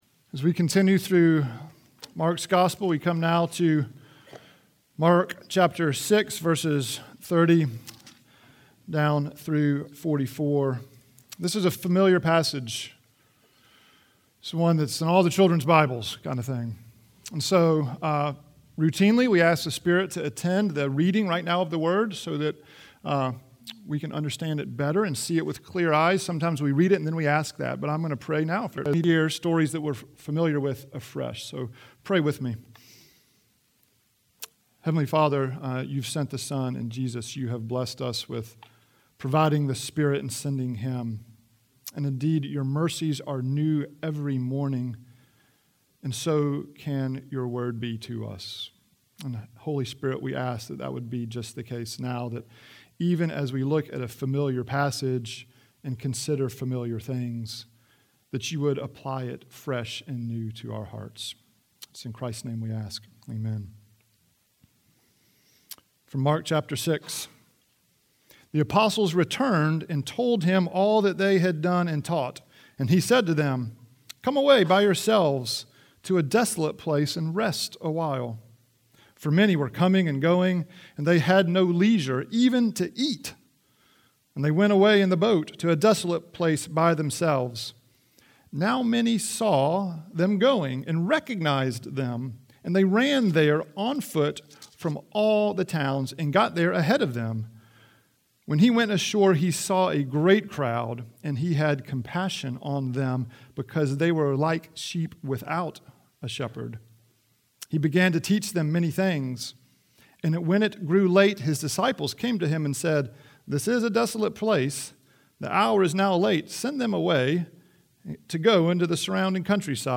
Trinity Presbyterian Church Sermons